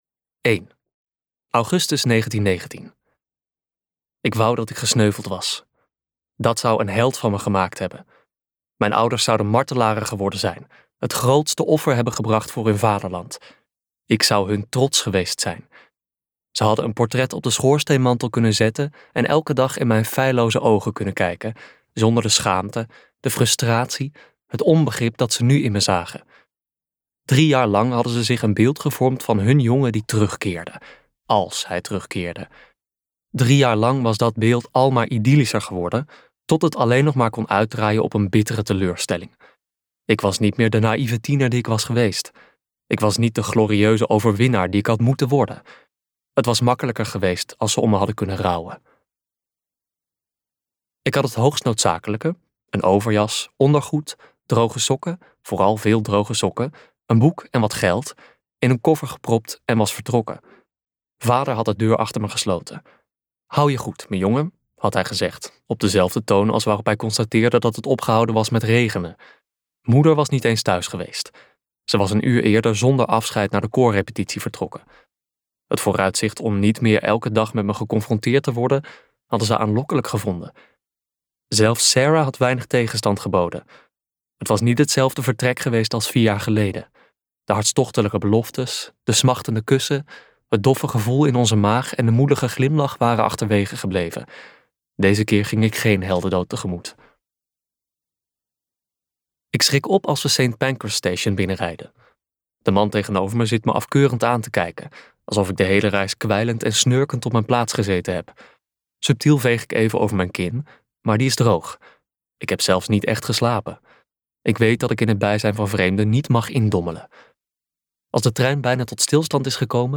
Ambo|Anthos uitgevers - Negentien negentien luisterboek